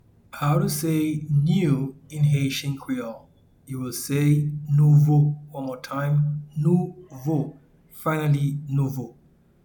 Pronunciation and Transcript: